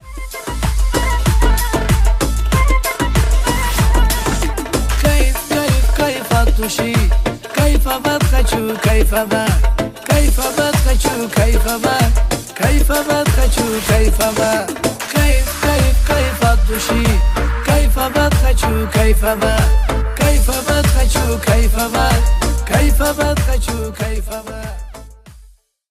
Танцевальные
кавказские